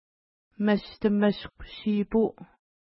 Pronunciation: mistəmiskw-ʃi:pu:
Pronunciation